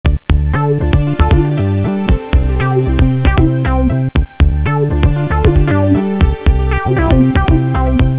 Music-On-Hold:
neutral, unterhaltsam und ohne Lücken